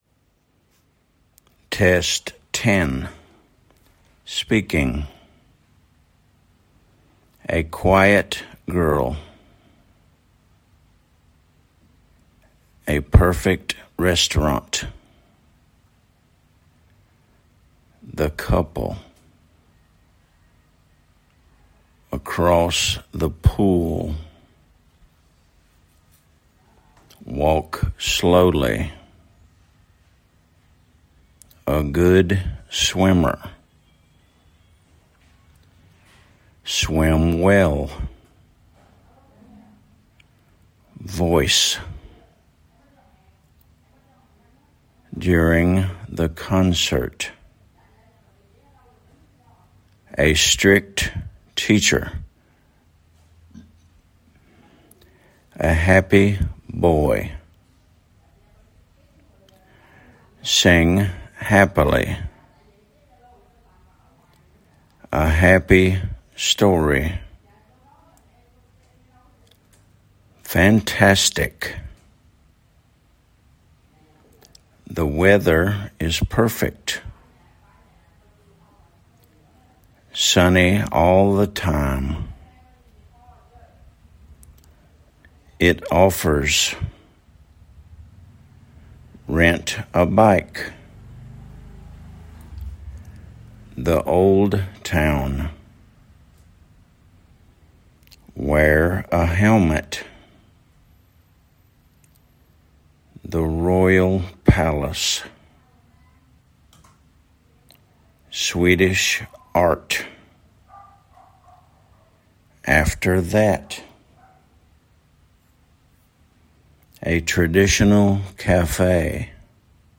a quiet girl /ə ˈkwaɪət ɡɜːl/
a perfect restaurant /ˈres.tə.rɑːnt/
across the pool /əˈkrɒs ðə puːl/